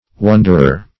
Wonderer \Won"der*er\, n.